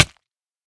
snapshot / assets / minecraft / sounds / damage / hit3.ogg
hit3.ogg